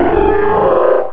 Cri de Séléroc dans Pokémon Rubis et Saphir.